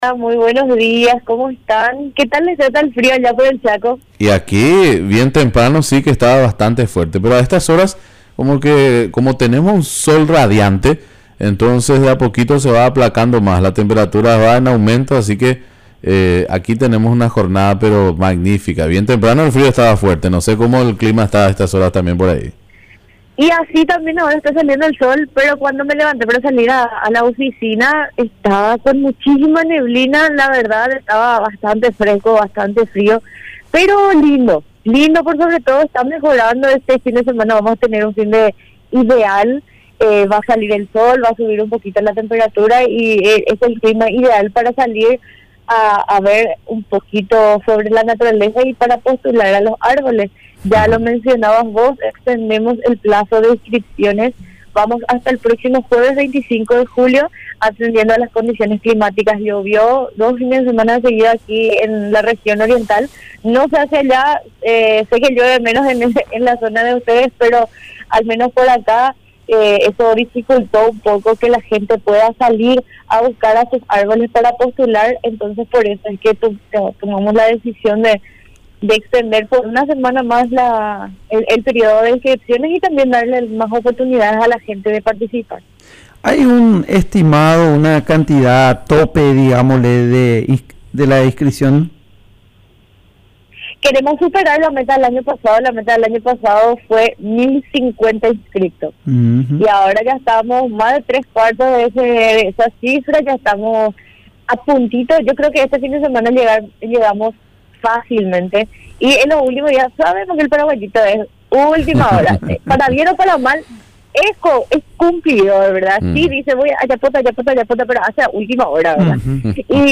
Entrevistas / Matinal 610 Colosos de la Tierra Jul 16 2024 | 00:16:11 1x 00:00 / 00:16:11 Subscribe Share RSS Feed Share Link Embed